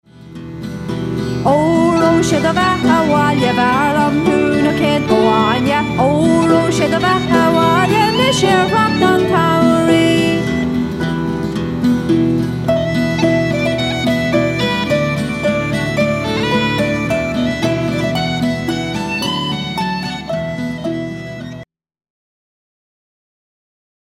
don’t remember the tempo being this fast, though!.
Guitar and vocals
Harp, Whistles and Flute
Uilleann Pipes and Whistle